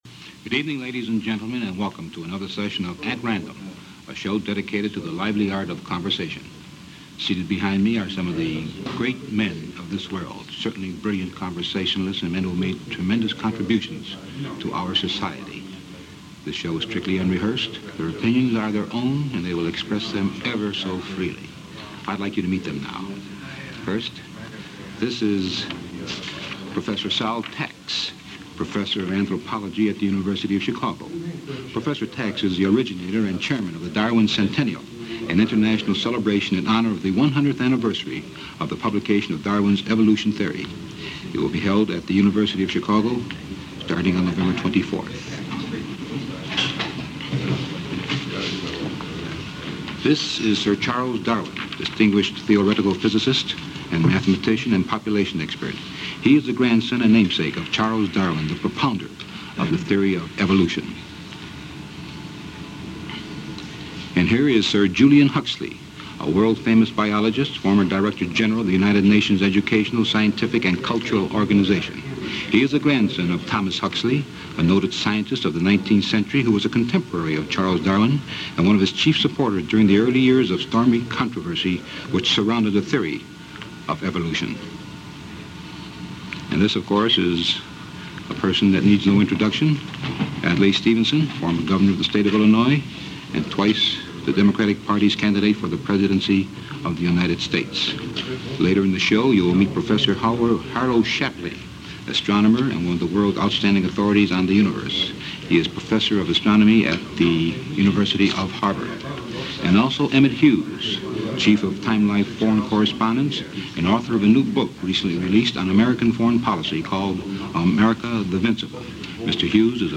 A discussion of the world as it was on August 13, 1960 – part of the At Random series hosted by Irv Kupcinet.
You could call this a historic gathering of thinkers and a lot of subjects are covered – and even at 90 minutes there was surely more to go, but sadly, the tape ran out.
Featured in this discussion are: Sir Charles Darwin (grandson of Theory of Evolution Charles Darwin) – Sir Julian Huxley (son of T.H. Huxley and brother of Aldous Huxley – Adlai Stevenson and Dr. Sol Tax from the University of Chicago, Anthropology Department.